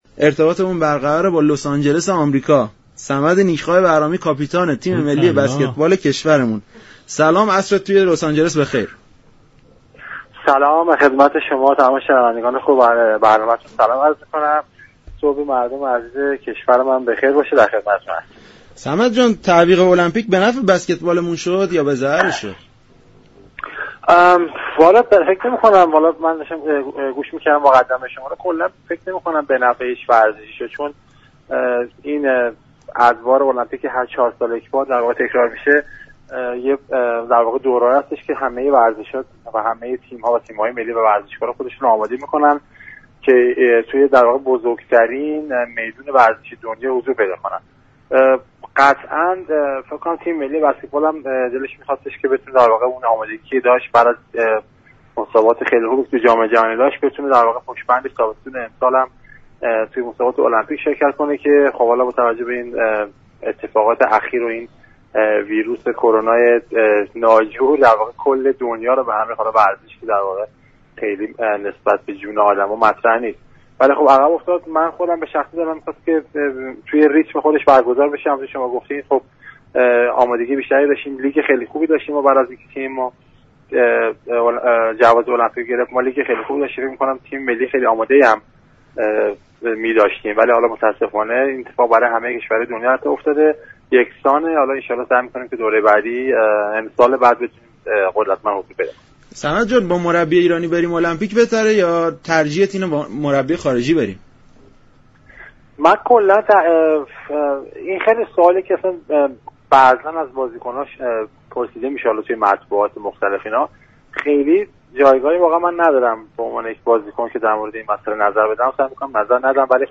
صمد نیكخواه بهرامی كاپیتان تیم ملی بسكتبال در برنامه سلام صبح بخیر گفت: ویروس كرونا برنامه ریزی ها را بر هم زد، تیم بسكتبال ایران برای بازی های المپیك آماده میدان بود.